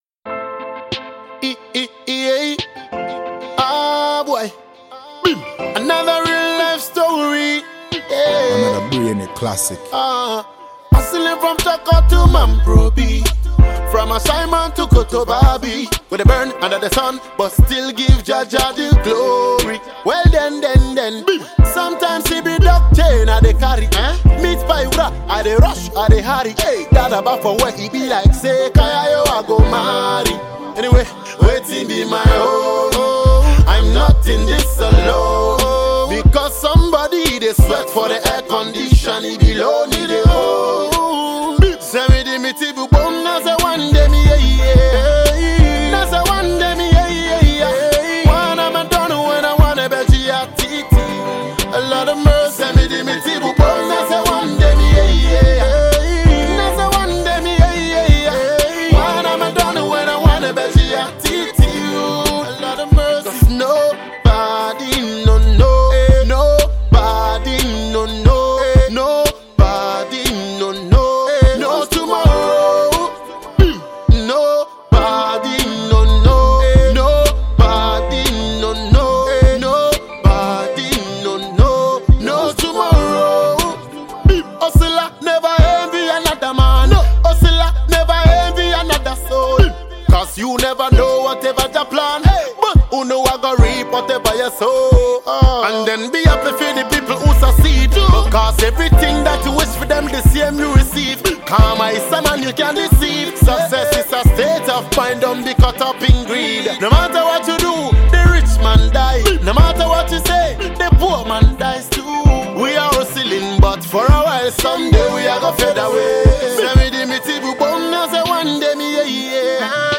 ” and is dancehall through and through.